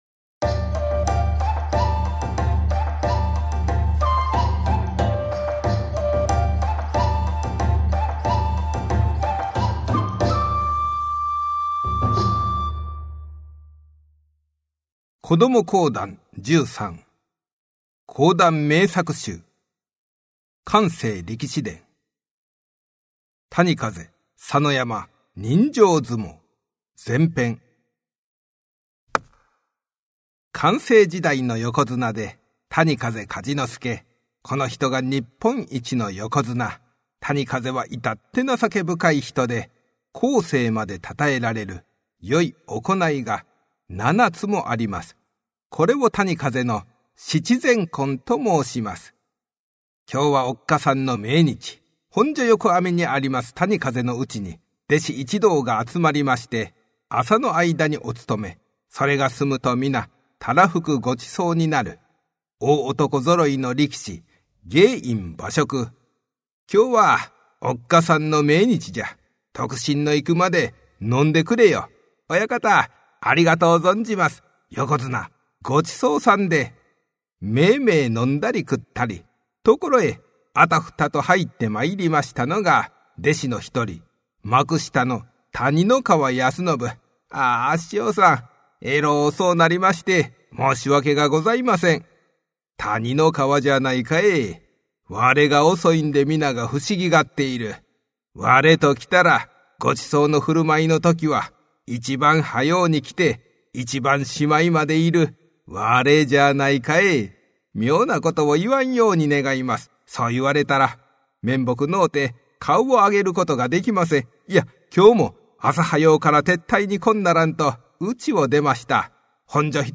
お子様向け短めの講談を楽しんでください♪